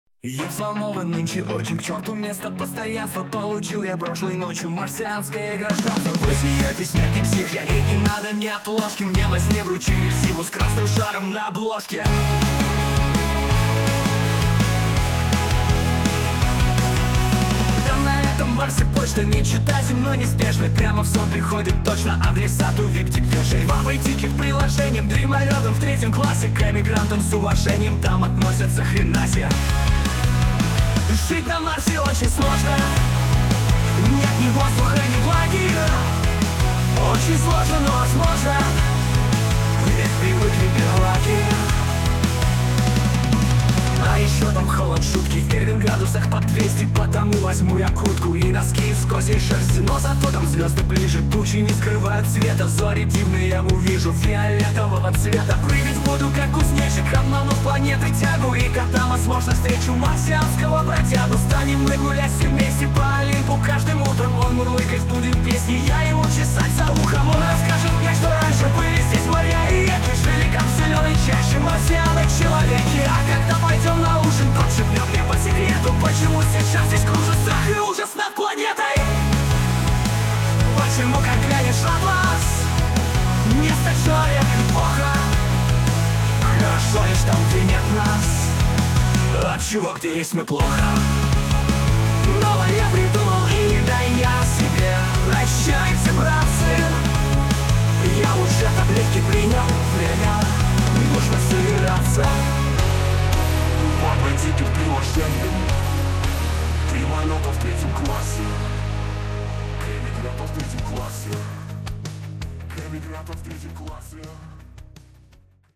marsianin.mp3 (2719k) indi punk отредактированный автор + suno ai